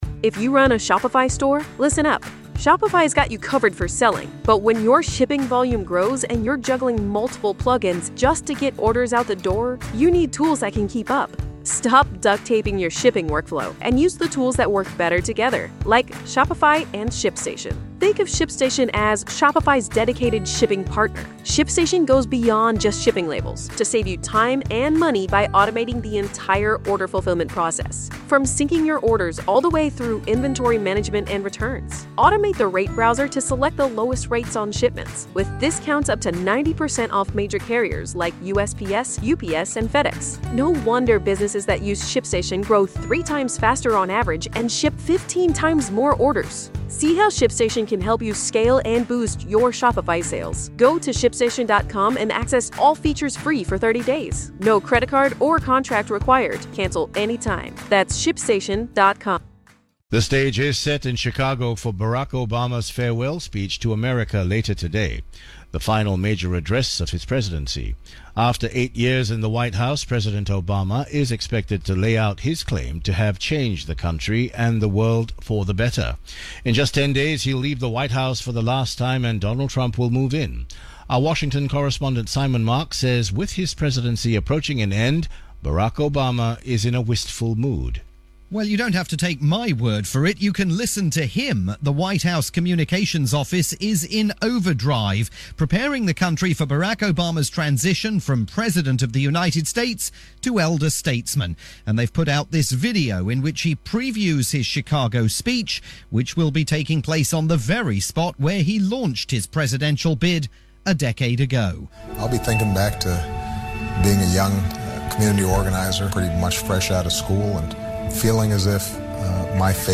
preview aired on radio stations worldwide.